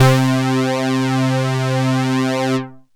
ANALOG 1 3.wav